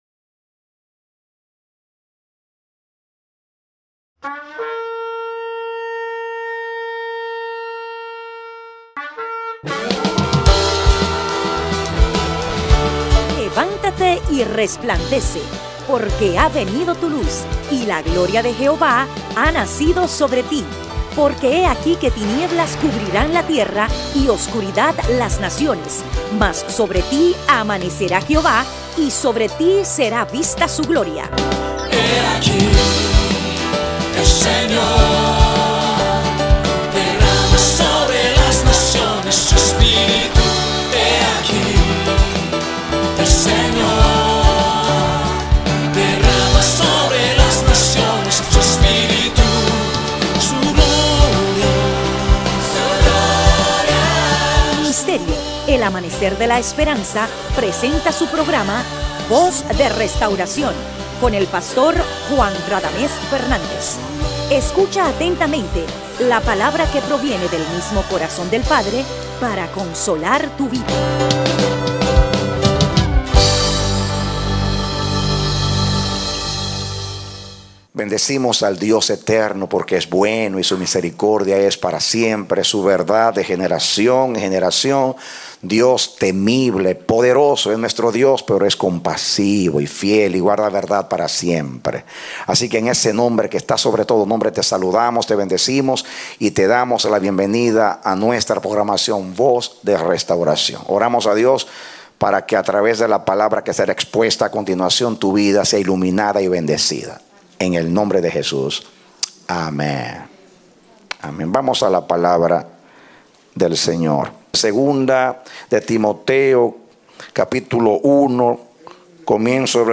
A mensaje from the serie "Mensajes."